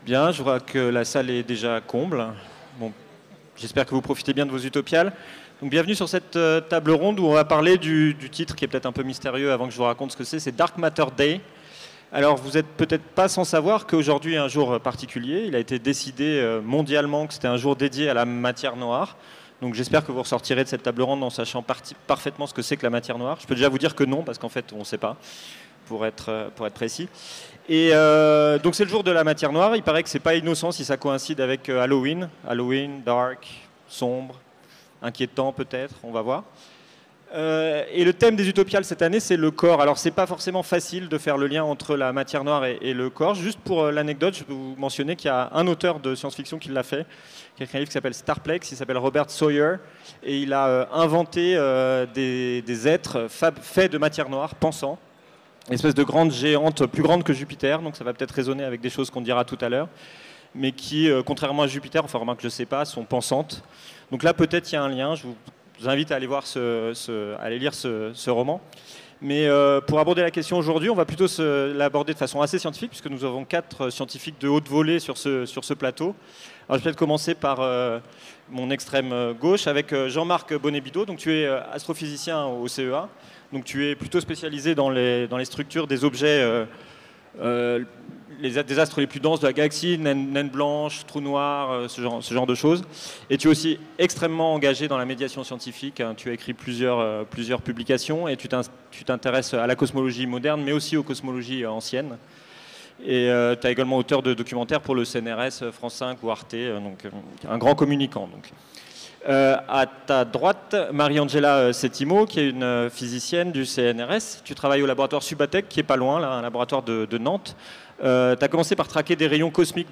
Conférence Dark Matter Day enregistrée aux Utopiales 2018